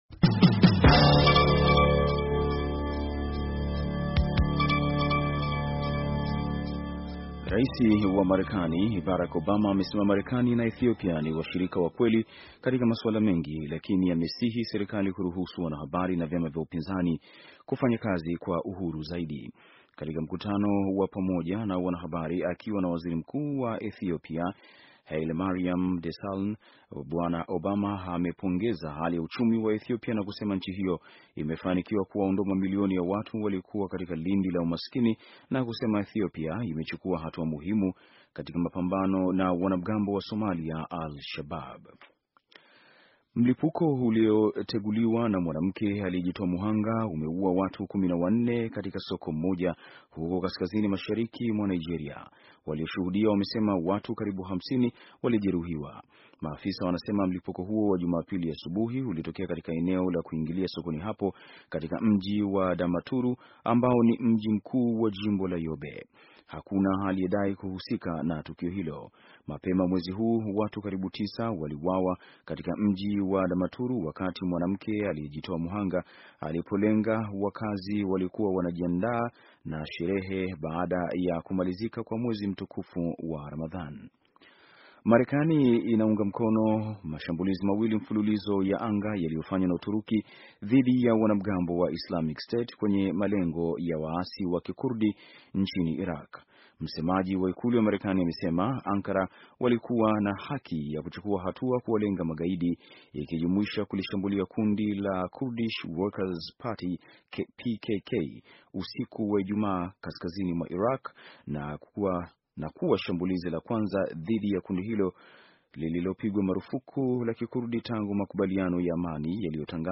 Taarifa ya habari - 4:37